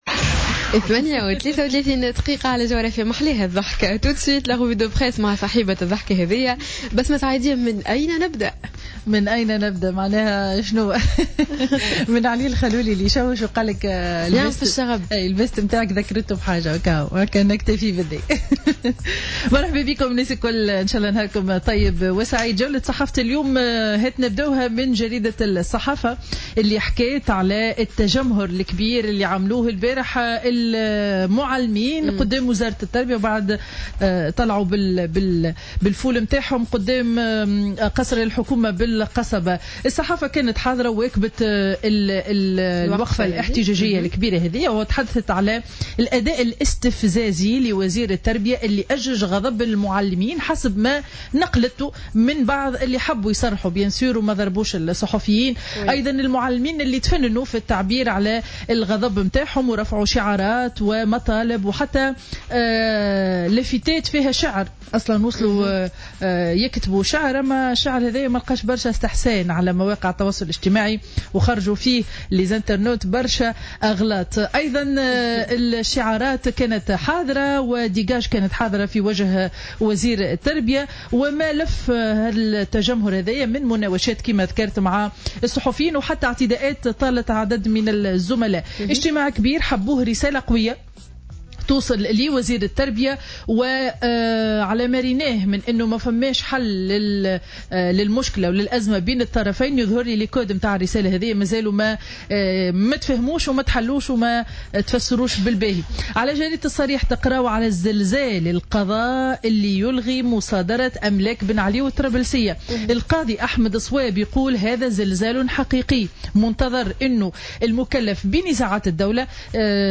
Revue de presse du mercredi 10 juin 2015